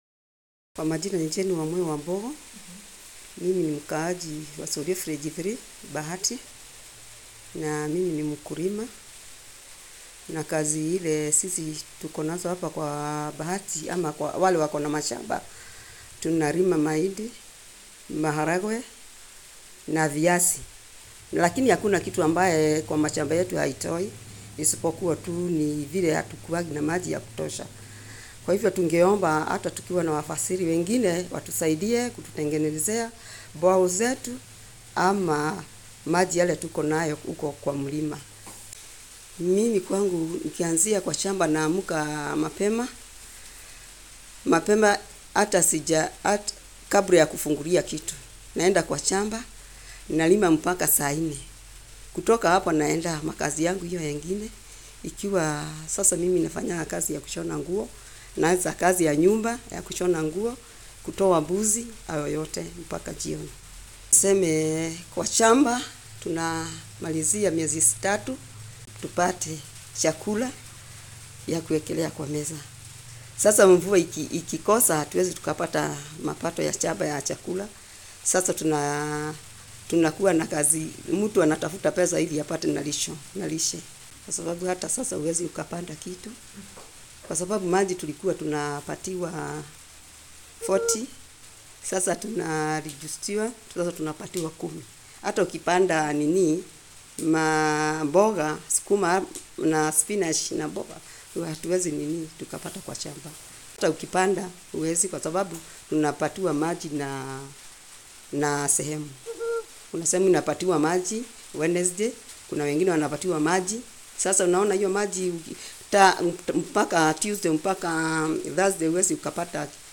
Interview als Audio (nicht übersetzt):